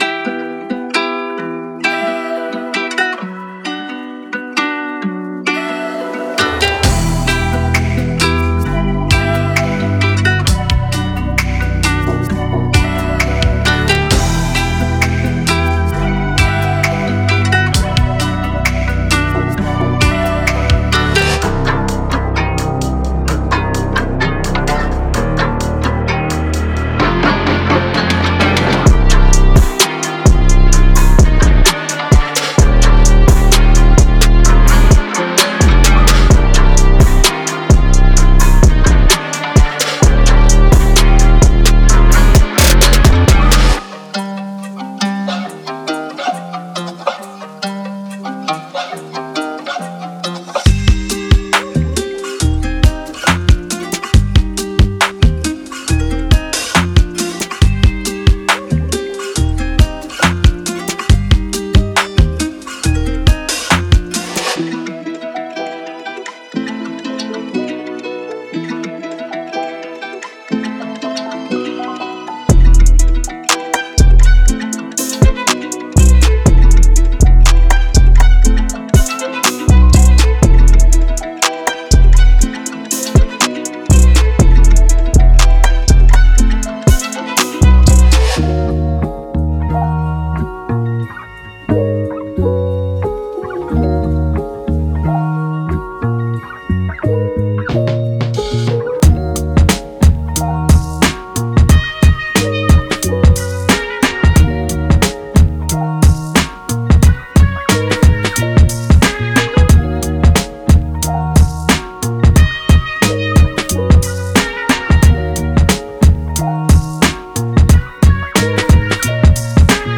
Genre:Trap
ラテン音楽から強い影響を受けており、このジャンル特有のコード進行を取り入れつつ、現代的で新鮮なエッジを加えています。
デモサウンドはコチラ↓